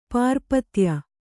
♪ pārpatya